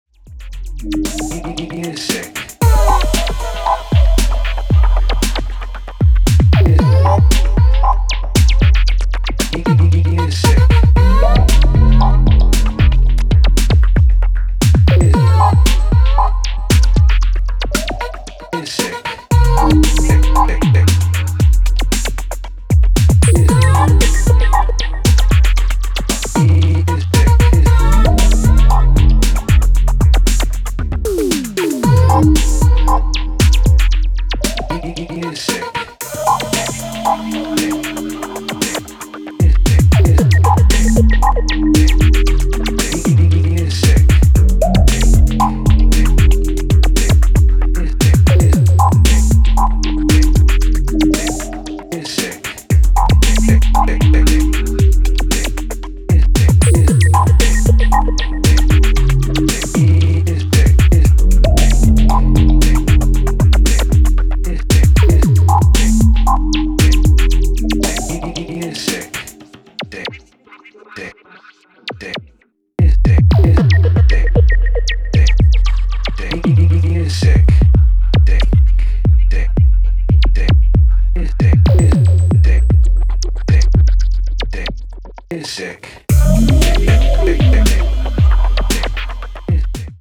Electro House Techno Bass Breaks